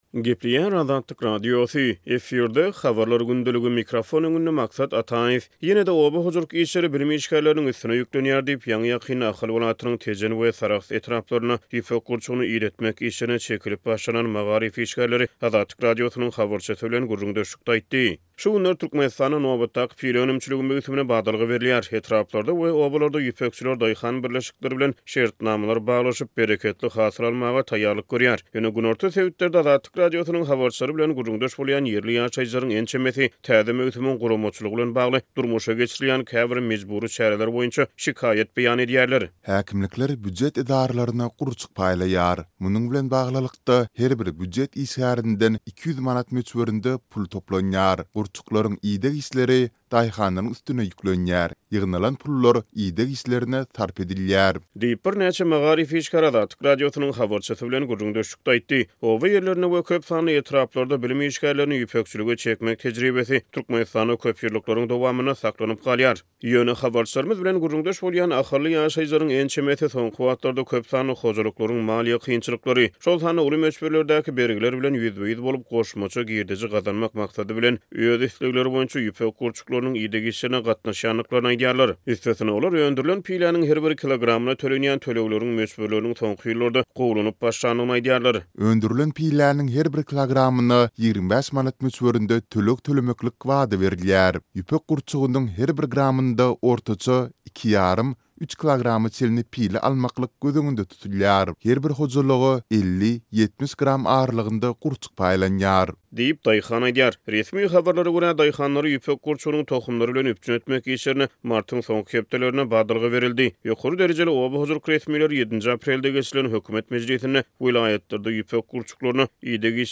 Ýene-de oba hojalyk işleri bilim işgärleriniň üstüne ýüklenýär diýip, ýaňy-ýakynda Ahal welaýatynyň Tejen we Sarahs etraplarynda ýüpek gurçugyny idetmek işlerine çekilip başlanan magaryf işgärleri Azatlyk Radiosynyň habarçysy bilen gürrüňdeşlikde aýtdy.